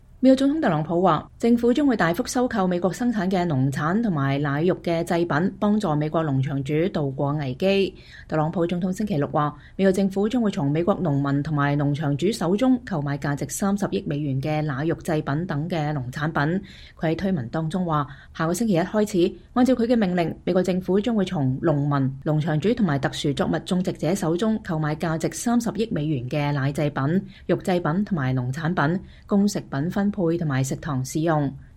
美國總統特朗普5月8日在白宮發表講話。